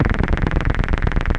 Loudest frequency 464 Hz Recorded with monotron delay and monotron - analogue ribbon synthesizer